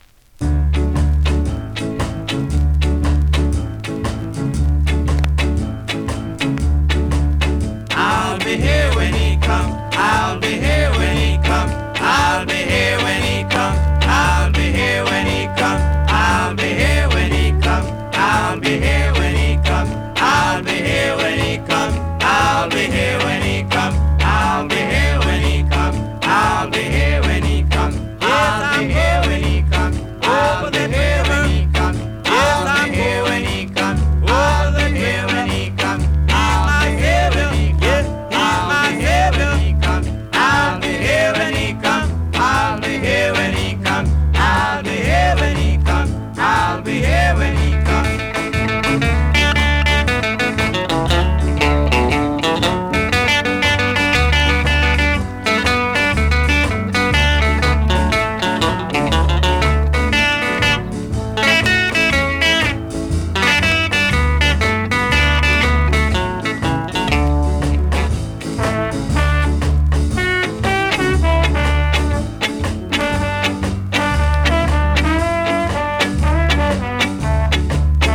スリキズ、ノイズ比較的少なめで